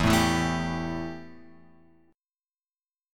Embb5 chord {0 0 2 2 x 3} chord